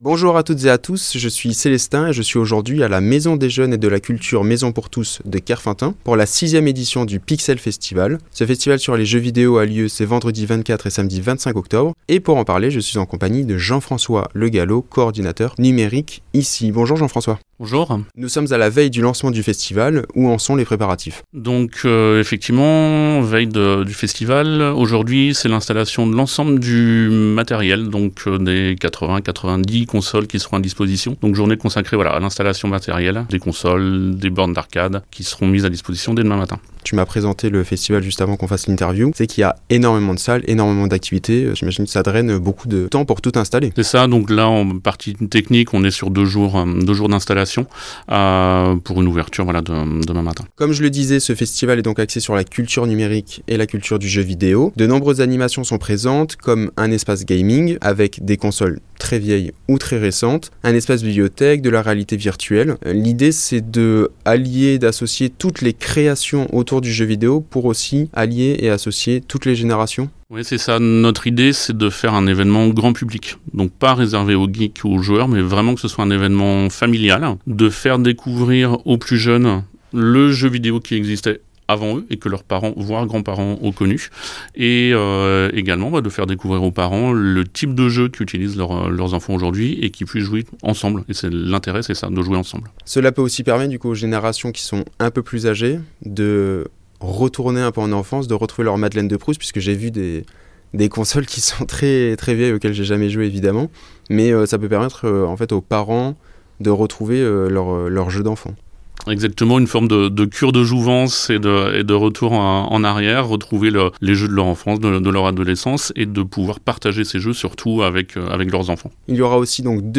À la veille de l’ouverture du Pixel Festival, Radio U s’est rendu à la MJC - MPT de Kerfeuntun pour découvrir les coulisses de la préparation de cette sixième édition.